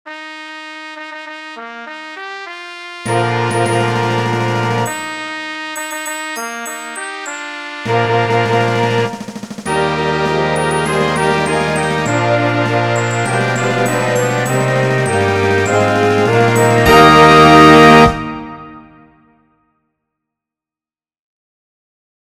Jest miły dla ucha oraz podkreśla nasz charakter – twierdzi burmistrz.